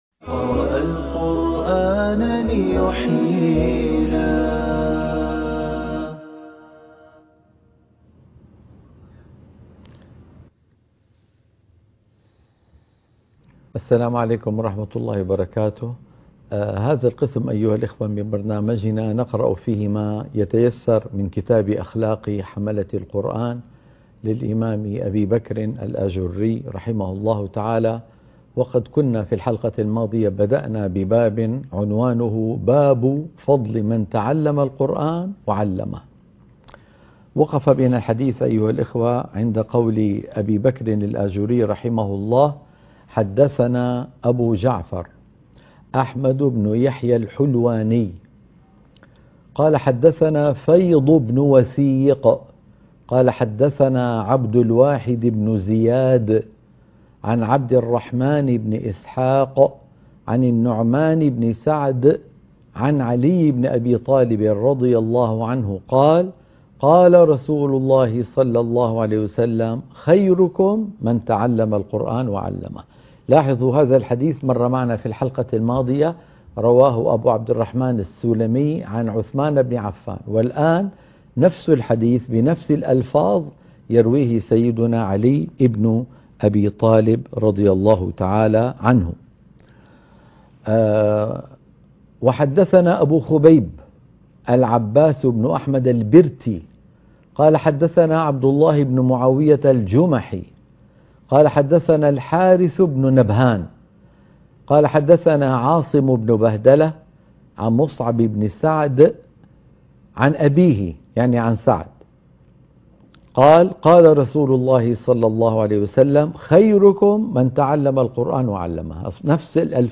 قراءة كتاب أخلاق حملة القرآن - الحلقة التاسعة